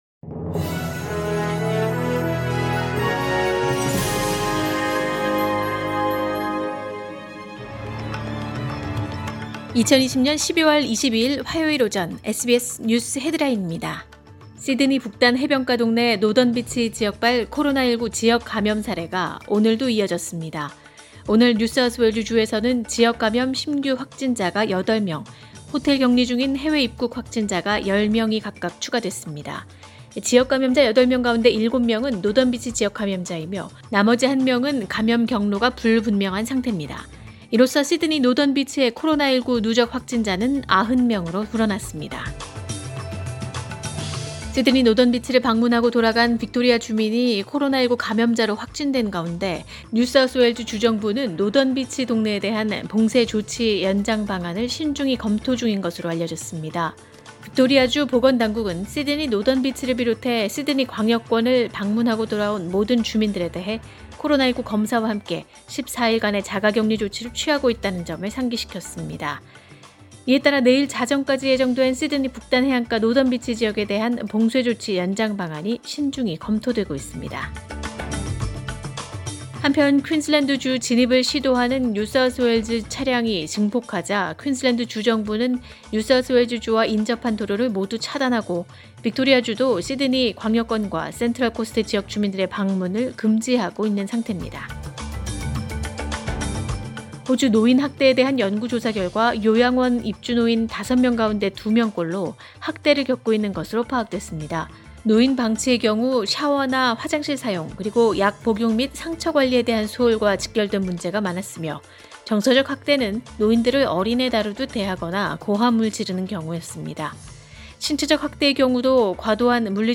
2020년 12월 22일 화요일 오전의 SBS 뉴스 헤드라인입니다.